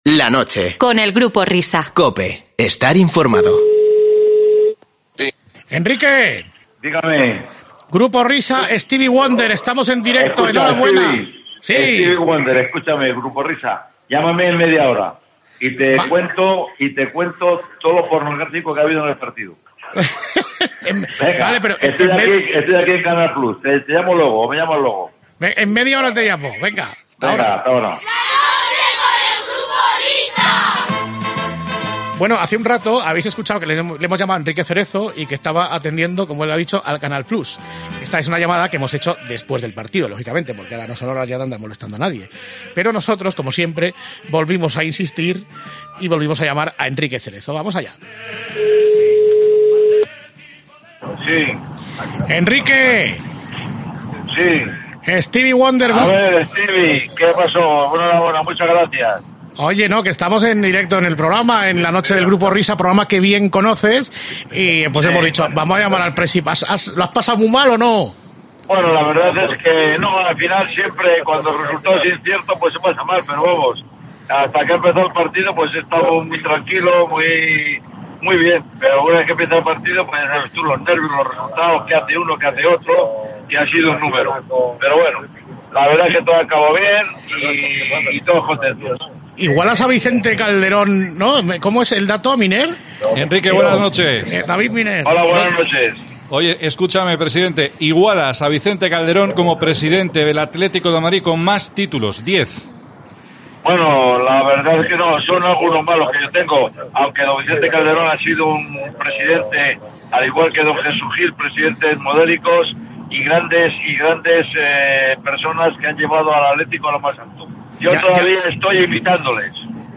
Las imitaciones más ingeniosas, el buen rollo, las entrevistas más amenas, una música distinta y la rebosante diversión, protagonizan un espacio respaldado de forma absoluta por la audiencia de COPE.
Presentan, entrevistan, cantan, imitan, inventan, parodian, pero sobre todo, crean.